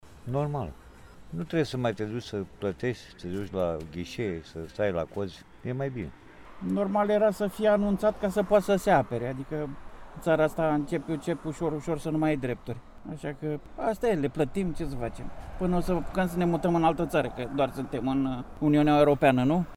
Opiniile șoferilor constănțeni cu privire la noul sistem sunt împărțite: